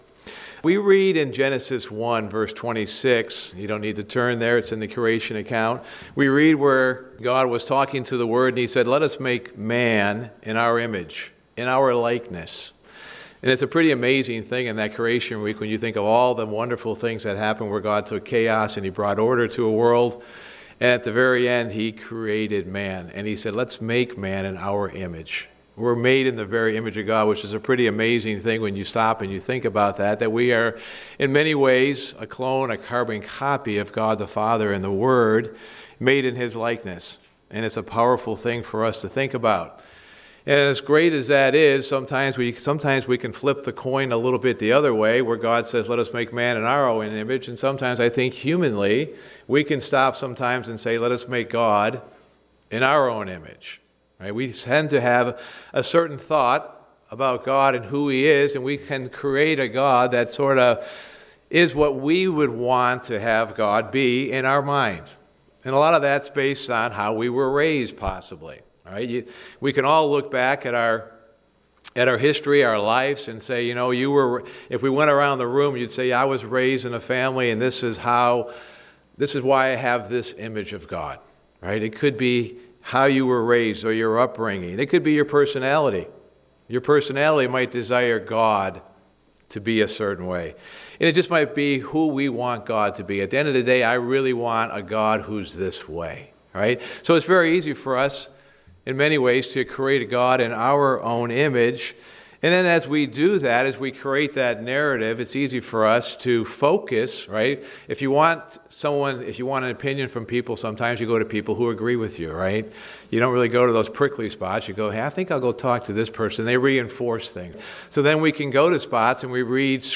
Sermon
Given in Ft. Wayne, IN